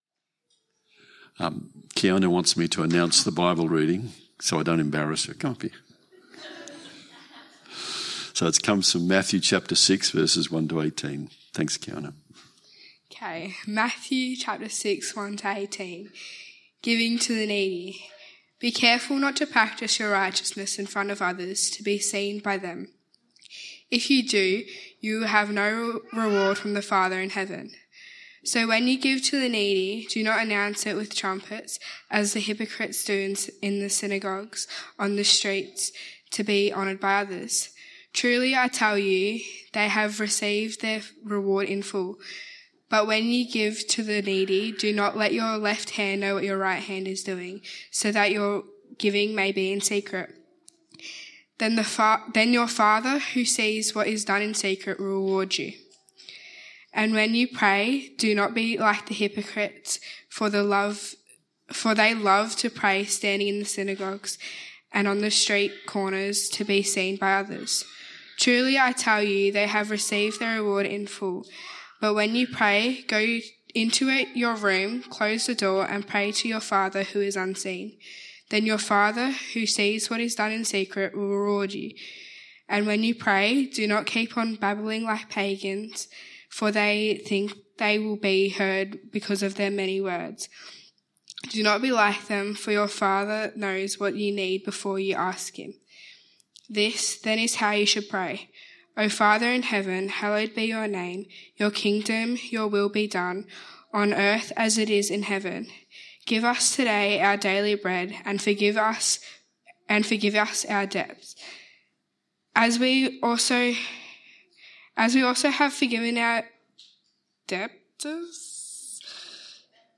We record the message most Sundays.
Weekly messages from Kerang Baptist Church, Kerang, Northern Victoria, Australia.